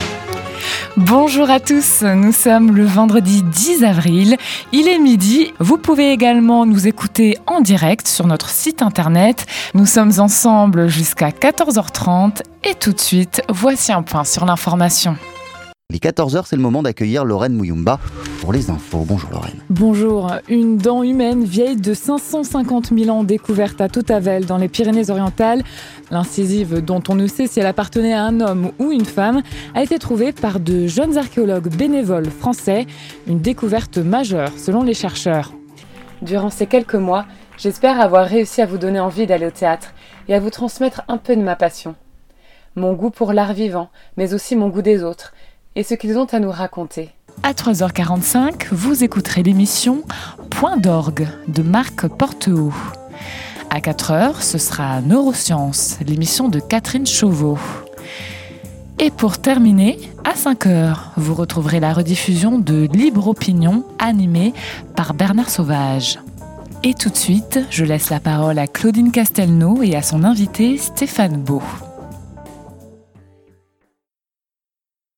Bandes-son
DEMO VOIX OFF